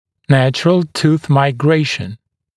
[‘næʧrəl tuːθ maɪ’greɪʃn][‘нэчрэл ту:с май’грэйшн]естественные перемещения зубов (миграция)